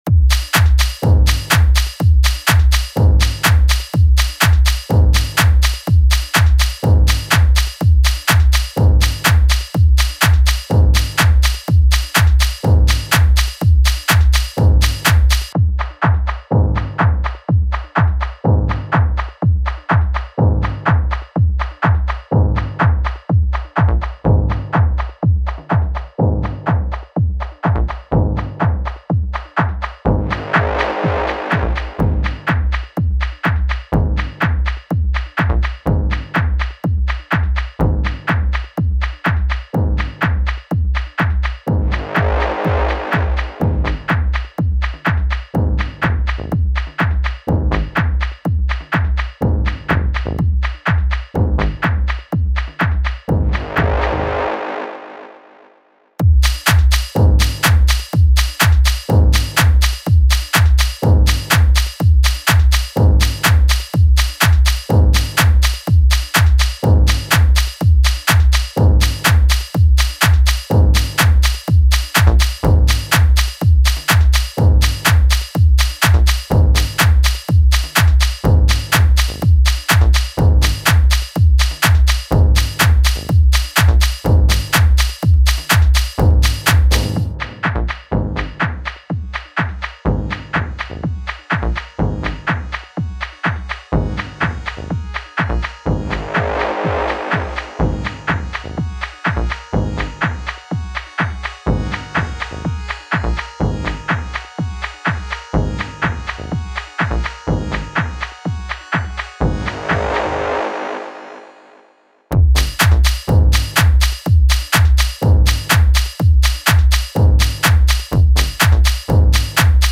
Tech House
Club Mix. Includes custom drummer and player sounds.